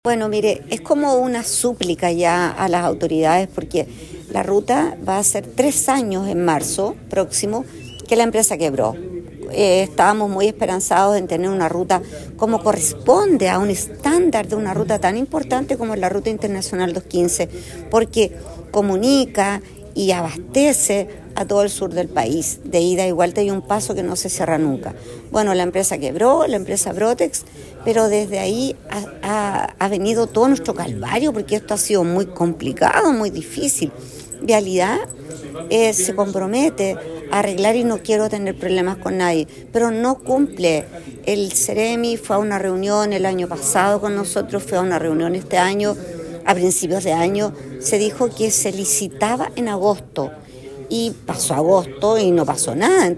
Del mismo modo, la jefa comunal enfatizó que hace cerca de tres años se encuentran a la espera de contar con una nueva ruta internacional, donde no se ha respondido a los compromisos adquiridos.